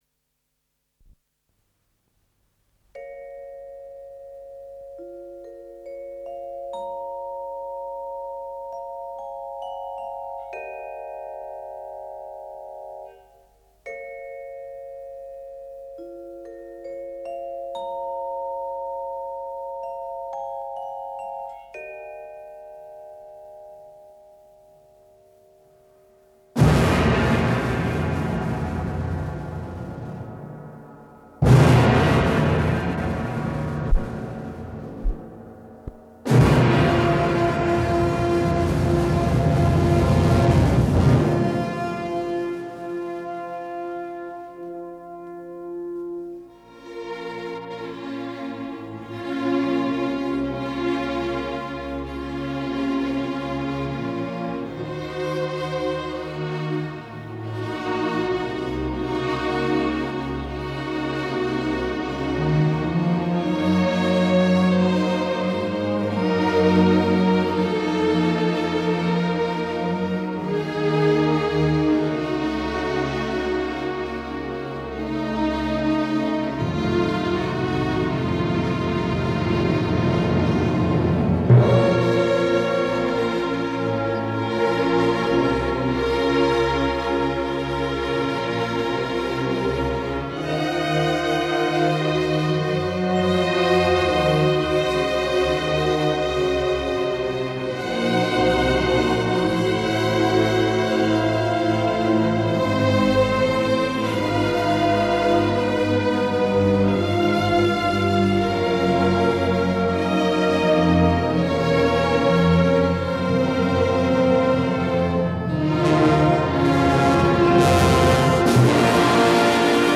Д. Кабалевский .Симфонический  оркестр ВР и Т. Дирижёр Д. Кабалевский.
Моно.